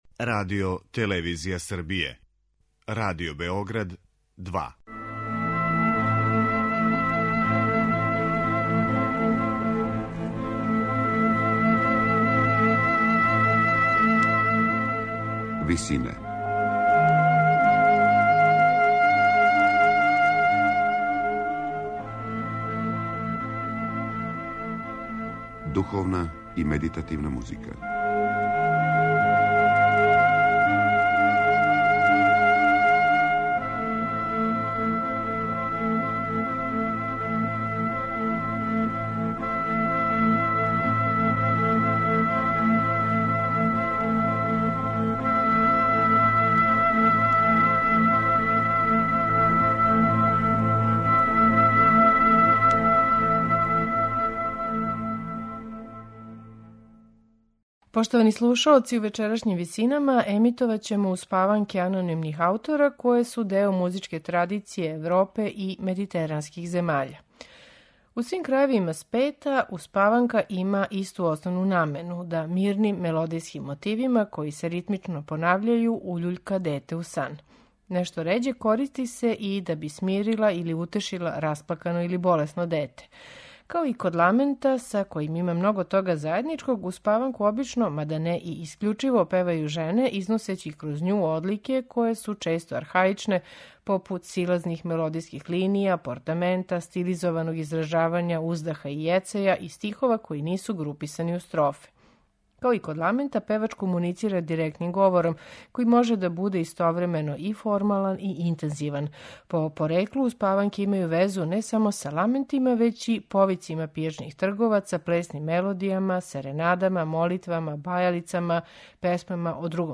У свим крајевима света, успаванка има исту основну намену - да мирним мелодијским мотивима који се ритмично понављају, уљуљка дете у сан.
Као и ламент, са којим има много тога заједничког, успаванку обично, мада не и искључиво, певају жене износећи кроз њу музичке одлике које су често архаичне, попут силазних мелодијских линија, портамента, стилизованог изражавања уздаха и јецања, и стихова који нису груписани у строфе.